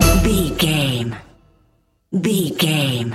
Aeolian/Minor
D
orchestra
harpsichord
silly
goofy
comical
cheerful
perky
Light hearted
quirky